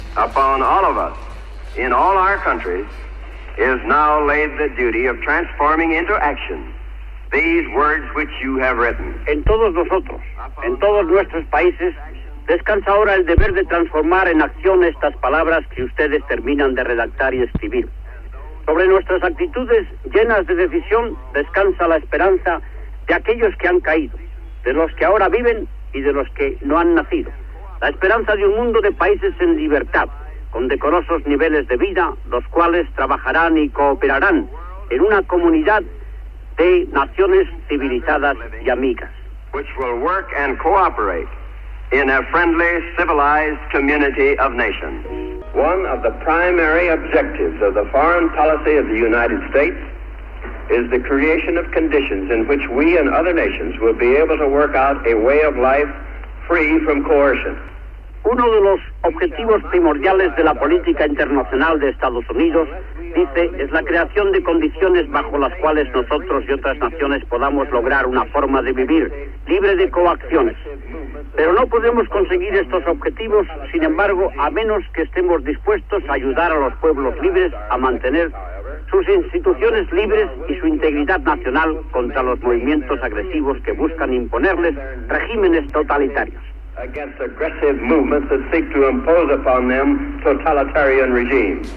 Obituari del president dels Estats Units Harry Truman amb enregistraments d'alguns dels seus discursos dels anys 1945 i 1948
Informatiu
Fragment extret del programa "Audios para recordar" de Radio 5 emès l'11 de desembre del 2017.